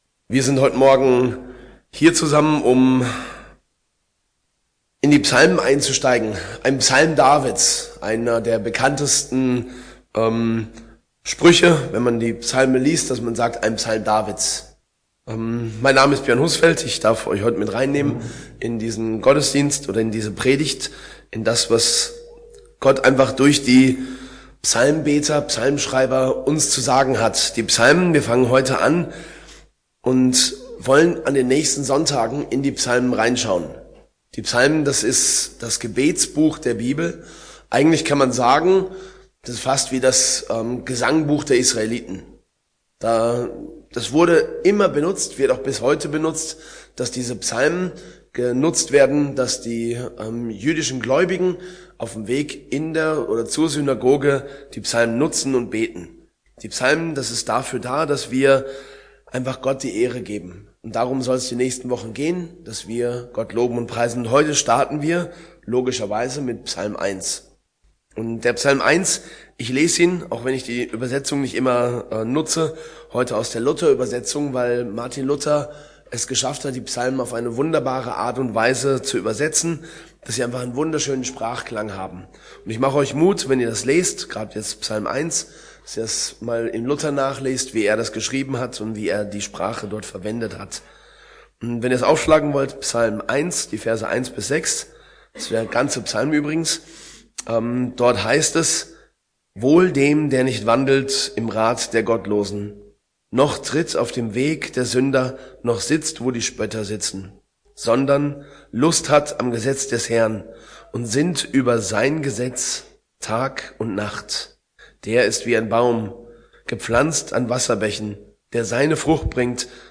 Frisch bleiben - Psalm 1 ~ Predigten aus der Fuggi